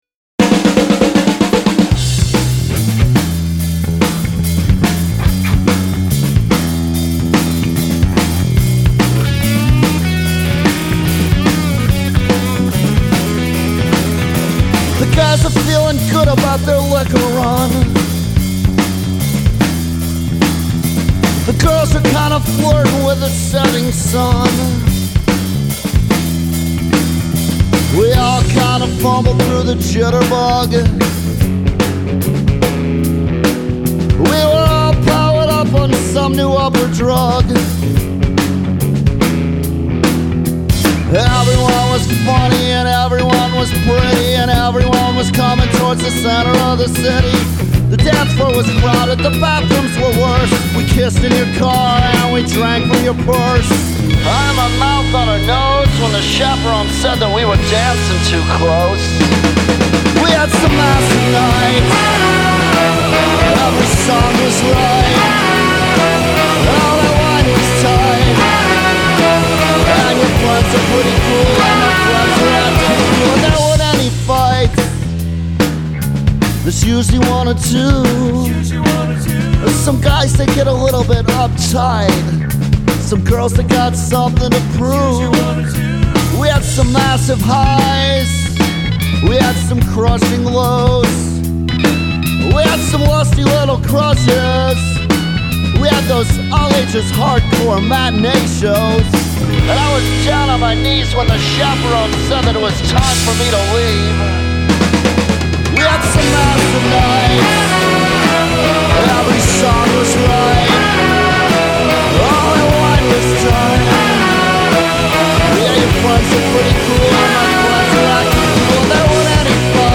Sounded so good cranked in the lake country.